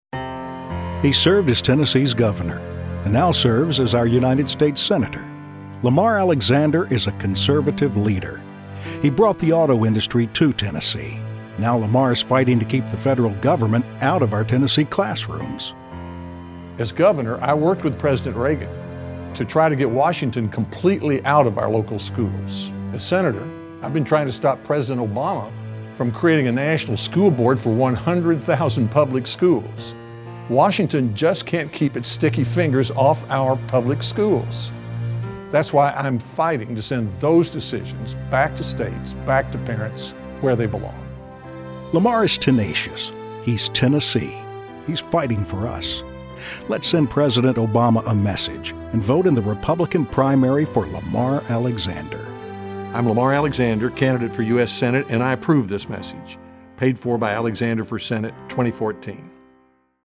"No National School Board" radio ad
The ads, which will begin airing statewide Sunday, July 20, starts with Alexander saying that as governor he worked with President Ronald Reagan to get Washington out of local schools, and that now he’s fighting to stop President Obama from exerting more control.